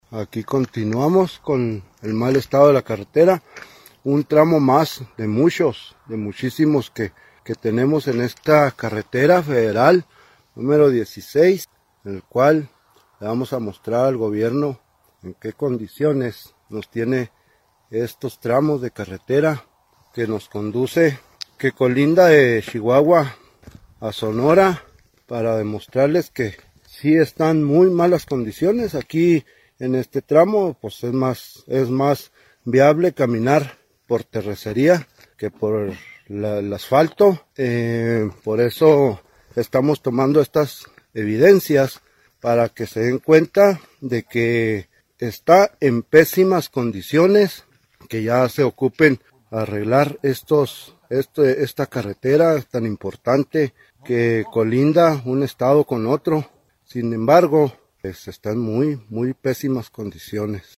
AUDIO: POBLADORES DE BASASEACHI-TOMICH